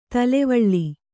♪ talevaḷḷi